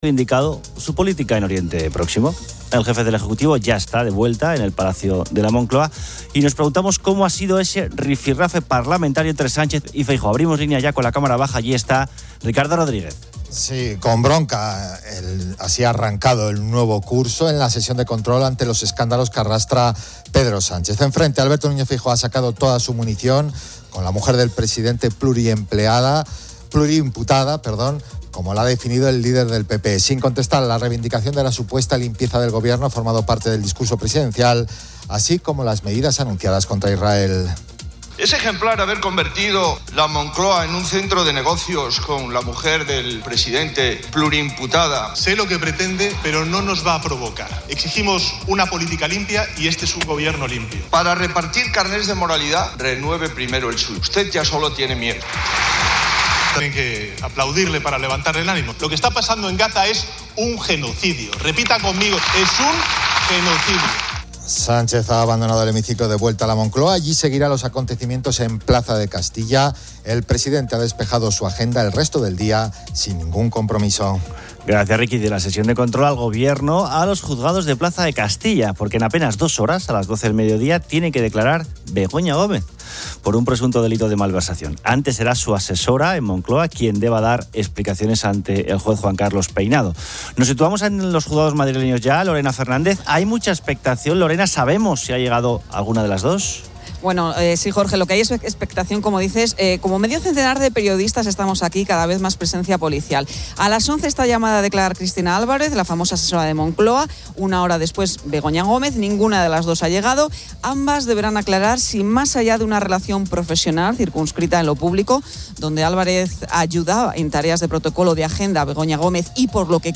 Oyentes relatan sueños premonitorios de desastres que se cumplen y el miedo a conducir en autovía.